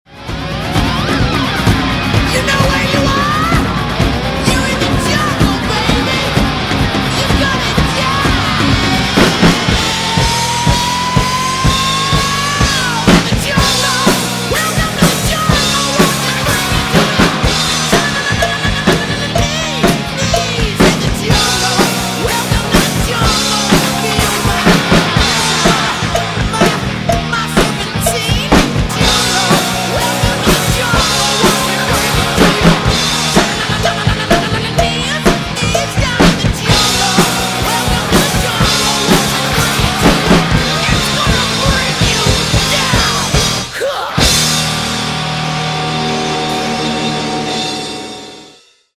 Drum Cover